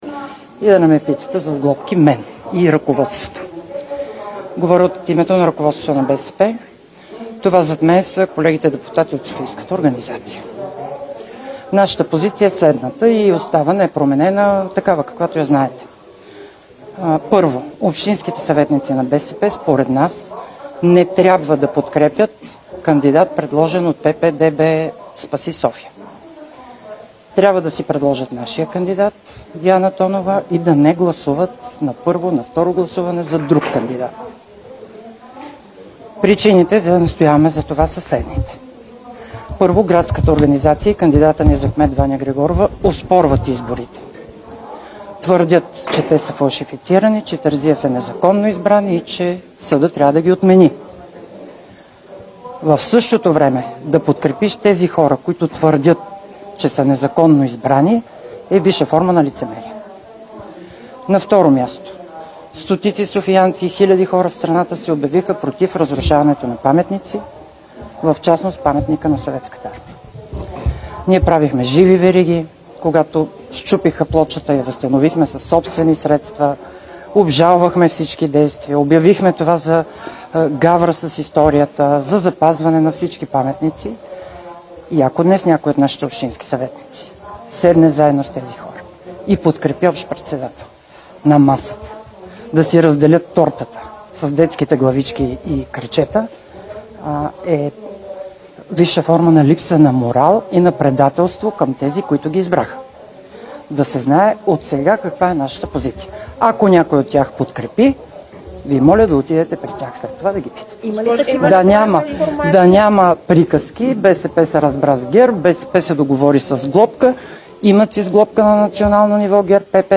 10.07 - Брифинг на председателя на ПГ на ДПС Делян Пеевски за отношенията с ГЕРБ и ПП-ДБ и предстящата ротация. - директно от мястото на събитието (Народното събрание)
Директно от мястото на събитието